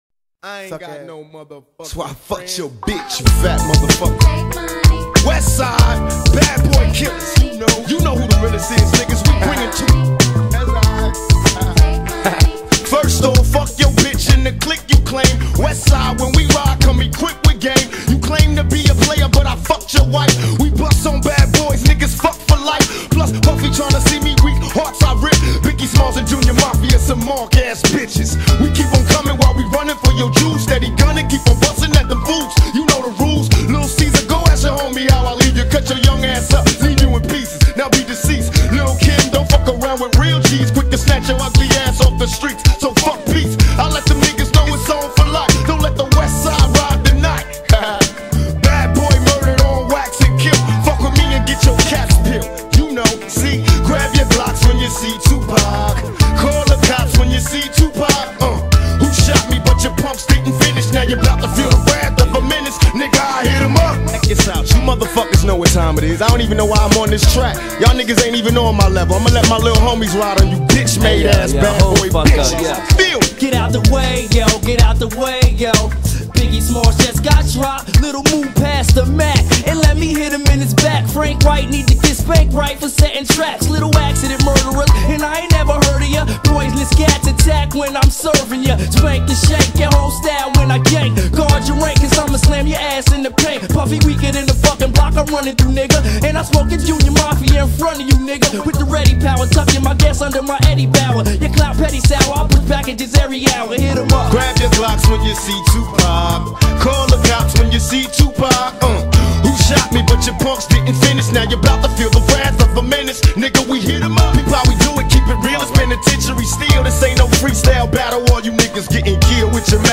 بهترین دیس ترک حال حاضر دنیا